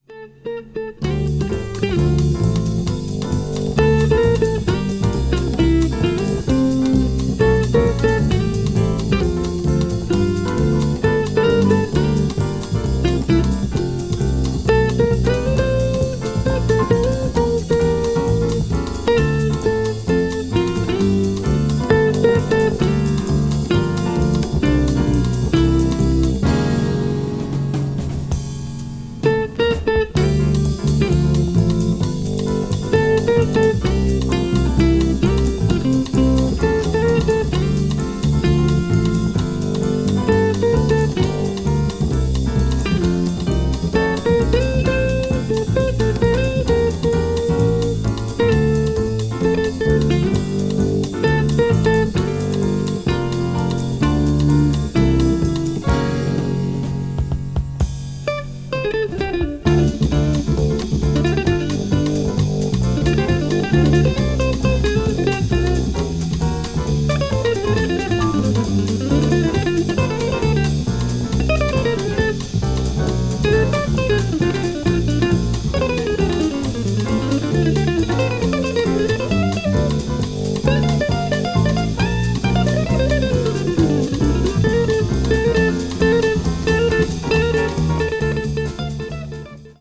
media : EX+/EX+(わずかにチリノイズが入る箇所あり)